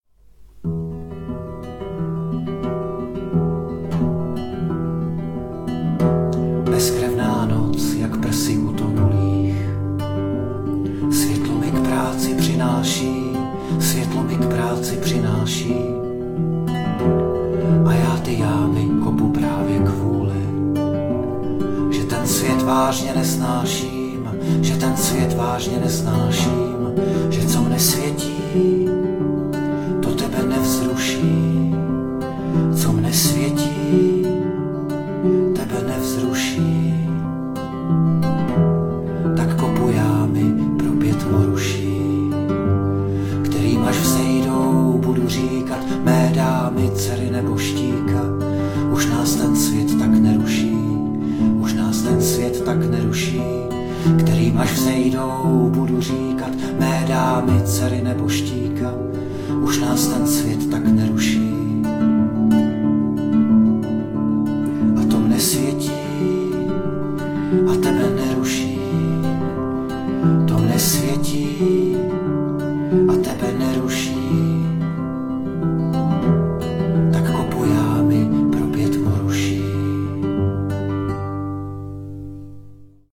Anotace: Starší věc v hudebním kabátku, kdysi vyšla v Knize přání a úmrtí, myslím, že to byl rok 2017...